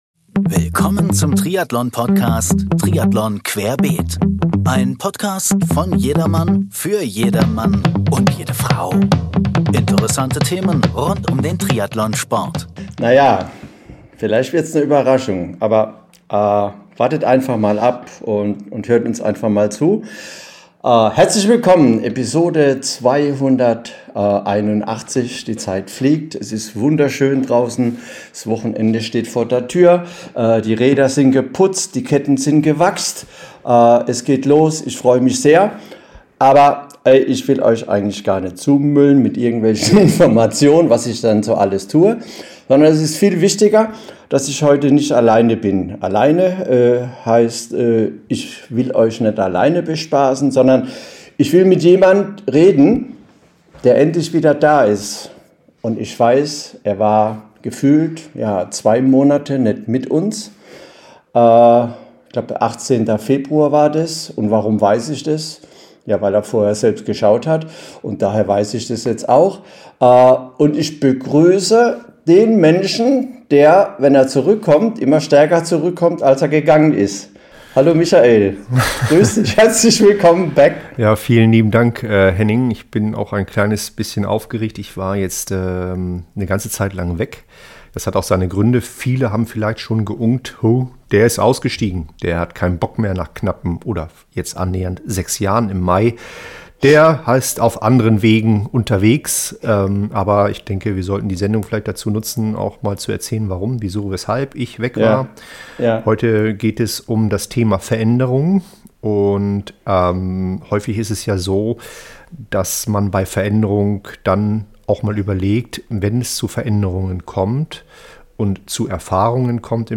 entsteht ein tiefgründiges Gespräch über Unsicherheit, Akzeptanz und die Kraft, im Wandel auch Chancen zu erkennen.